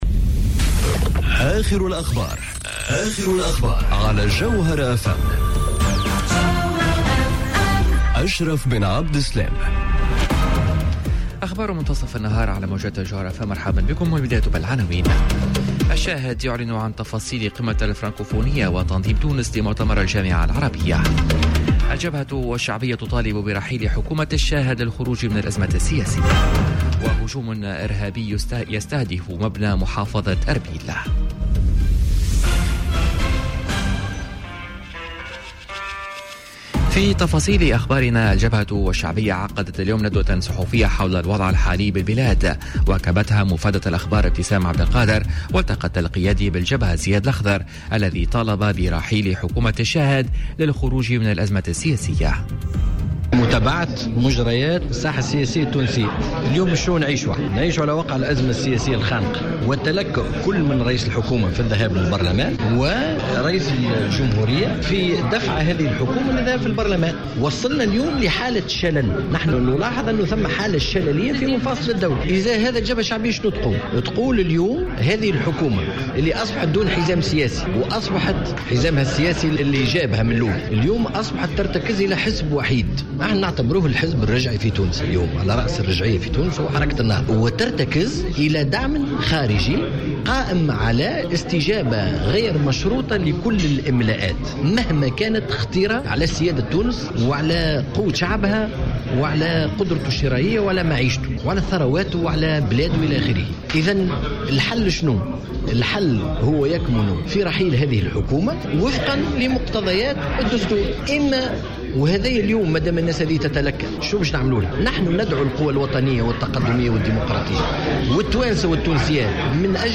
نشرة أخبار منتصف النهار ليوم الإثنين 23 جويلية 2018